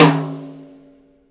078 - Tom-3.wav